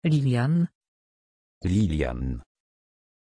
Pronunciation of Liliann
pronunciation-liliann-pl.mp3